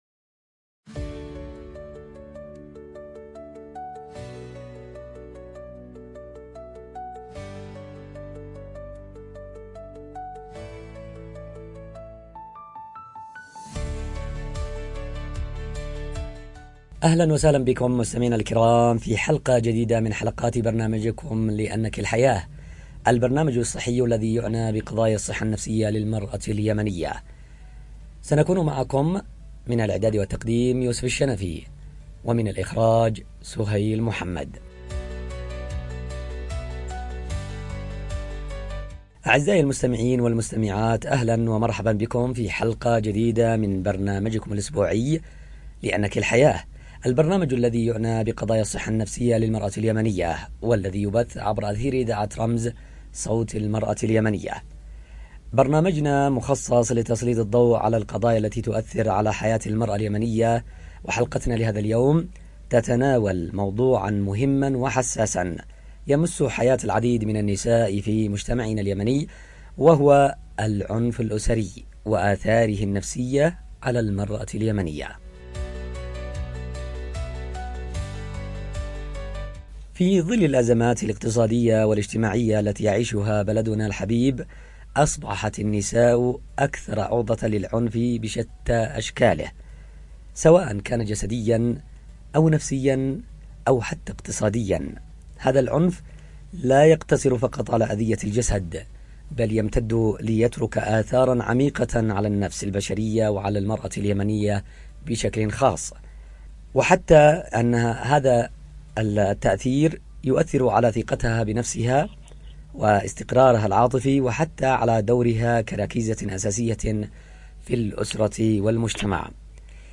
في نقاش عميق حول الأثر الخفي للايذاء الأسري وتأثيره العاطفي والسلوكي على النساء اليمنيات، ودور الأسرة والمجتمع المحلي في الوقاية والدعم والتعافي.